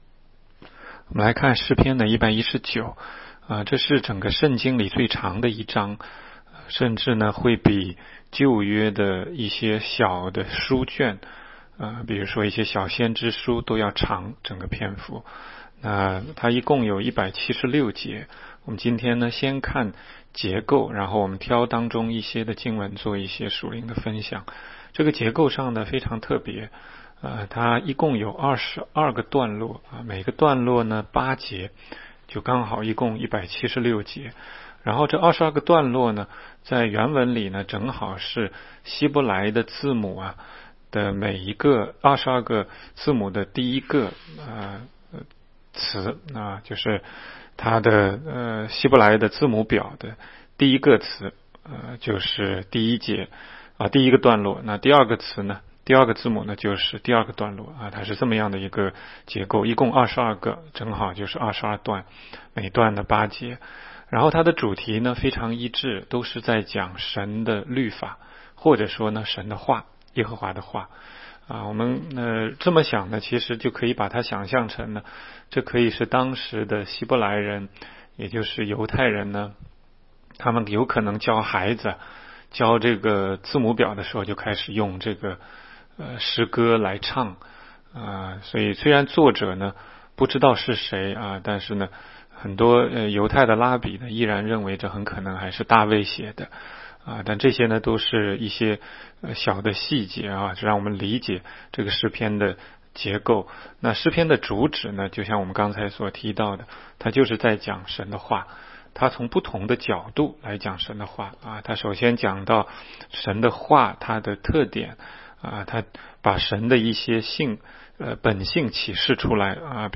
16街讲道录音 - 每日读经 -《 诗篇》119章